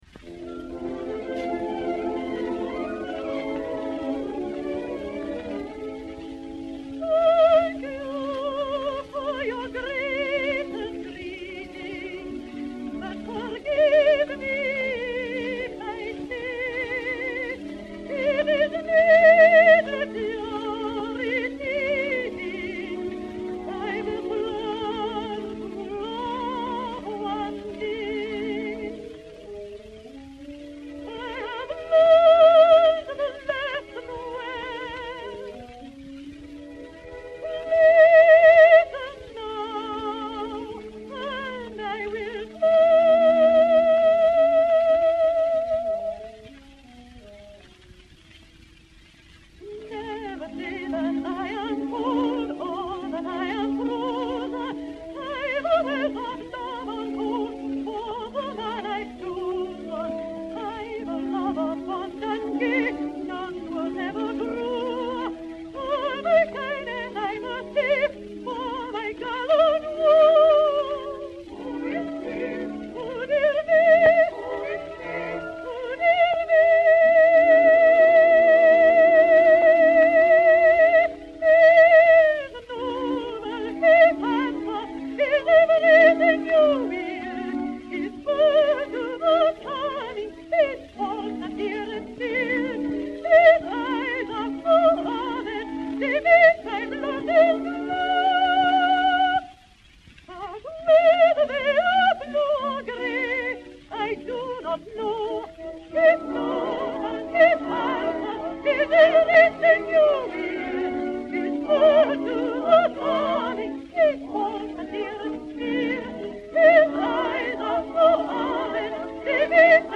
extraits avec les créateurs
Chœurs
enr. à Londres en 1919